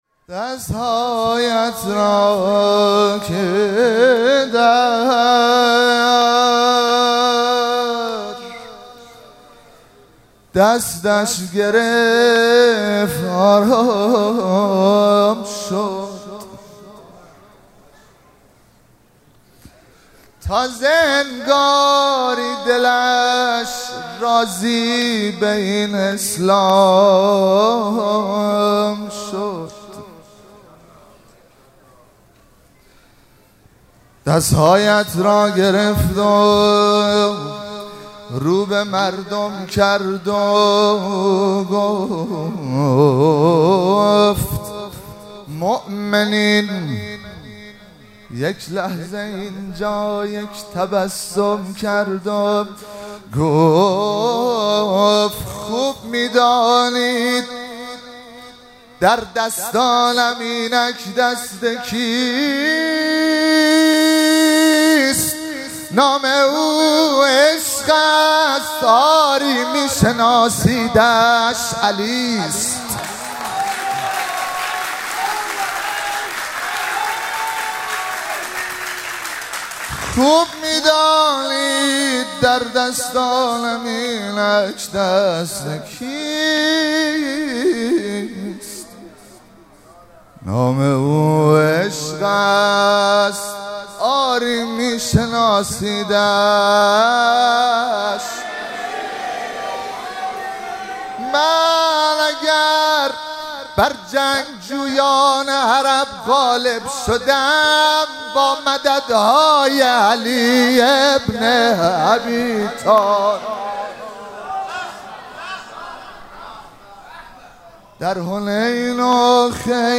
مراسم جشن شب دوم ویژه برنامه عید سعید غدیر خم 1444
مدح- دست ‌هایت را که در دستش گرفت آرام شد